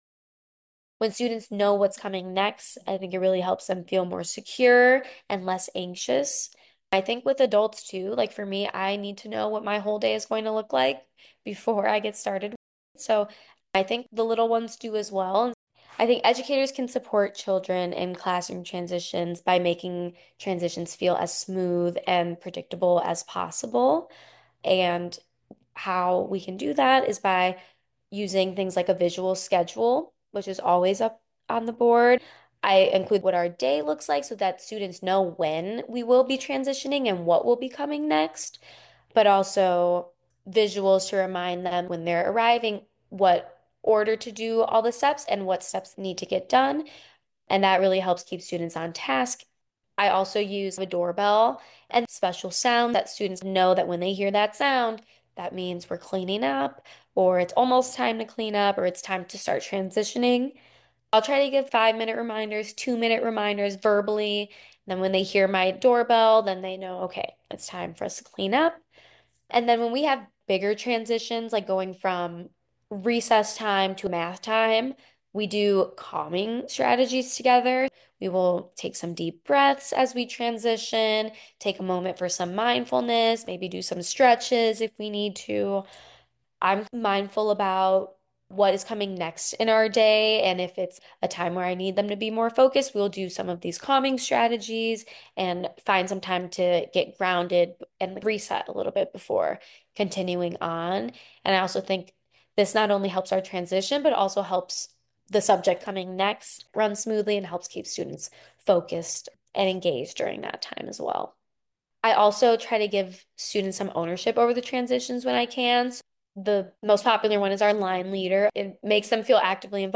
In this interview
Kindergarten teacher